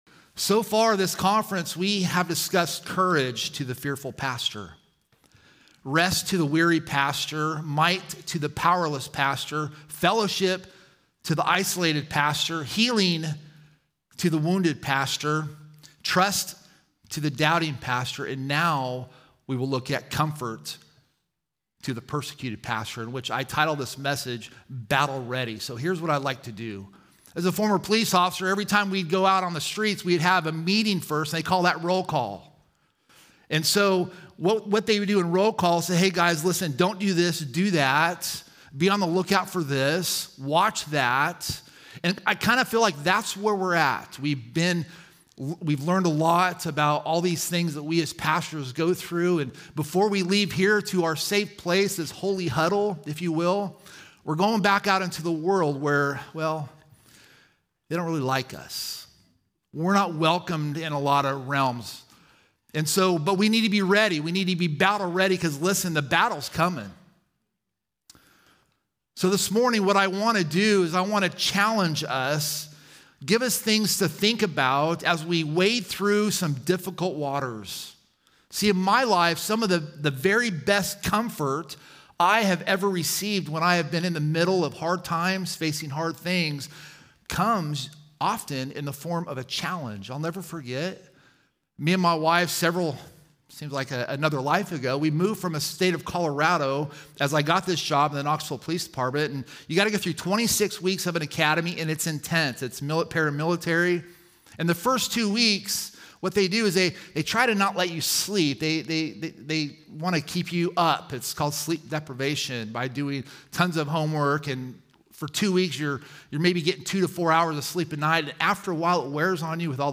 Home » Sermons » “The Persecuted Pastor”